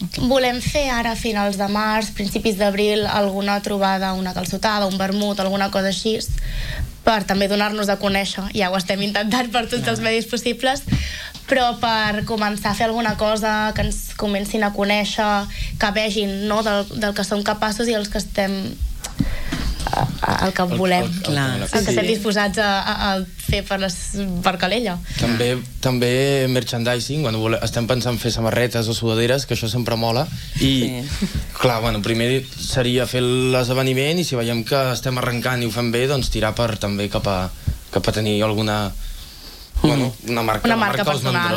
Aquest dilluns, una representació de l’associació ha passat pel matinal de RCT per explicar els seus projectes entre els que hi ha crear una marca que els identifiqui.